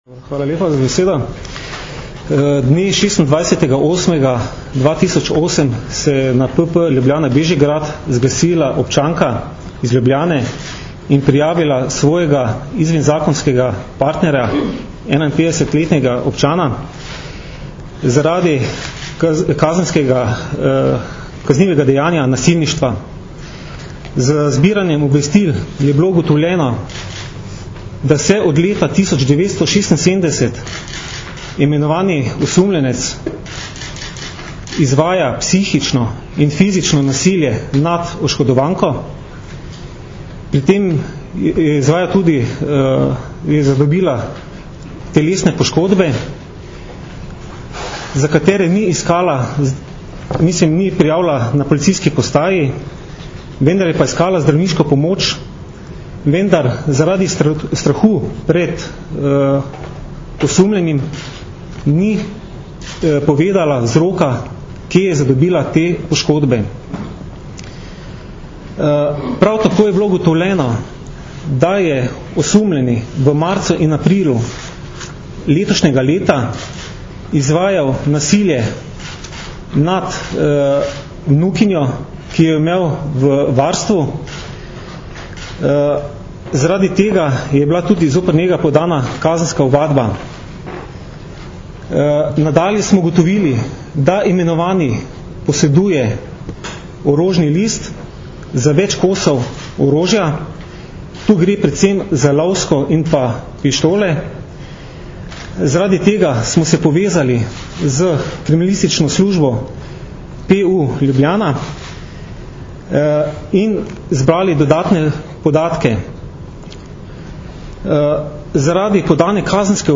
Zaseg večje količine orožja in streliva, problematika ilegalne trgovine in prometa z orožjem - informacija z novinarske konference